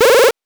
8 bits Elements
powerup_37.wav